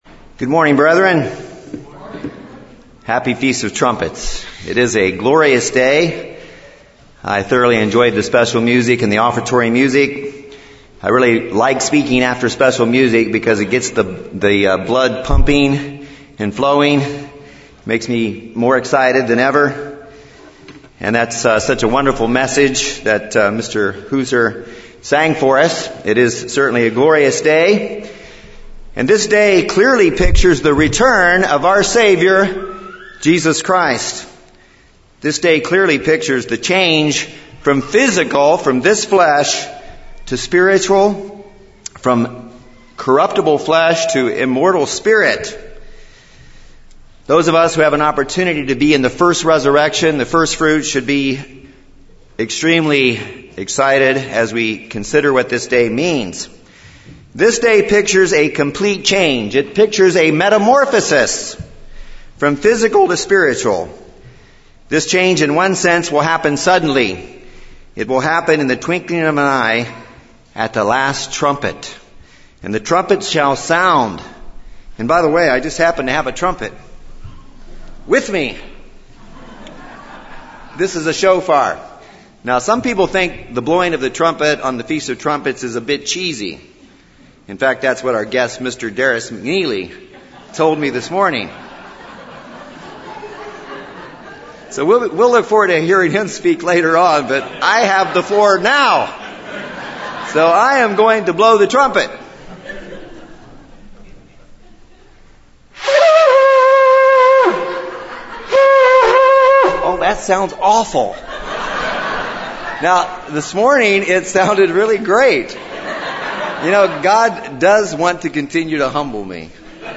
Today, on the Feast of Trumpets I would like us to consider our spiritual metamorphosis, our spiritual change as first-fruits upon the return of Jesus Christ.